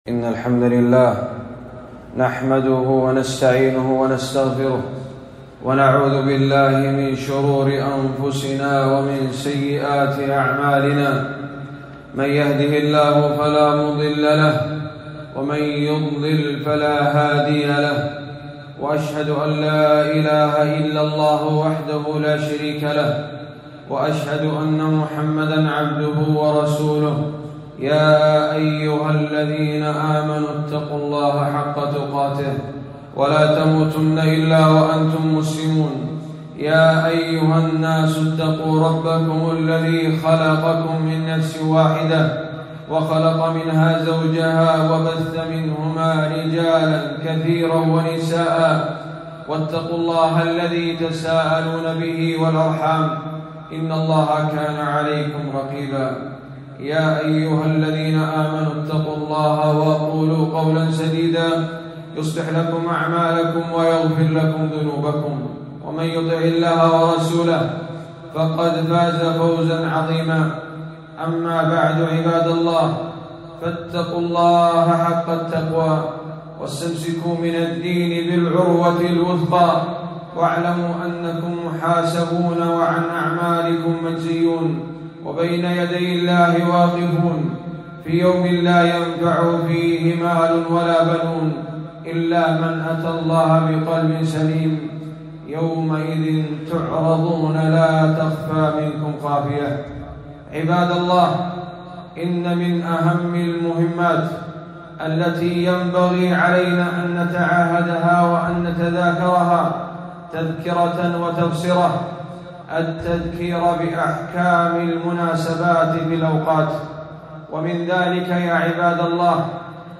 خطبة - مسائل وأحكام في زكاة الحبوب والثمار